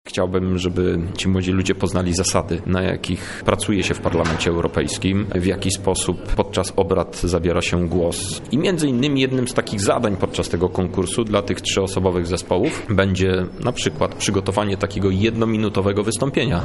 O szczegółach mówi organizator Krzysztof Hetman